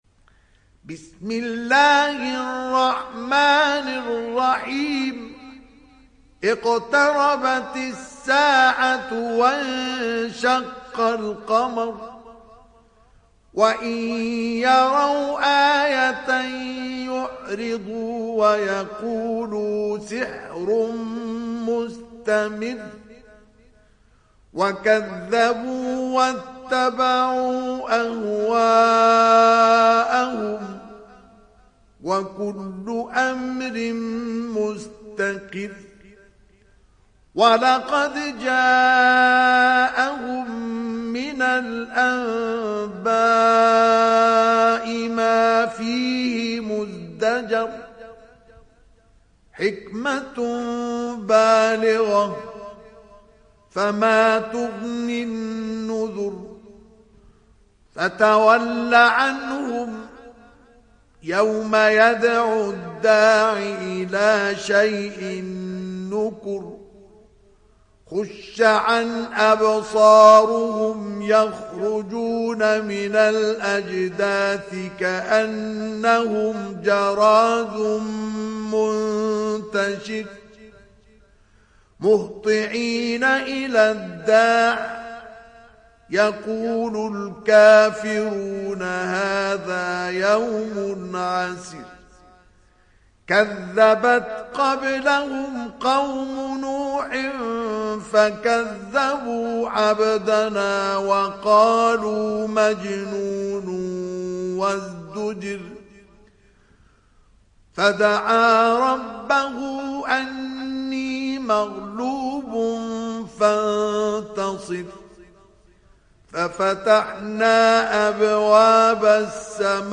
تحميل سورة القمر mp3 بصوت مصطفى إسماعيل برواية حفص عن عاصم, تحميل استماع القرآن الكريم على الجوال mp3 كاملا بروابط مباشرة وسريعة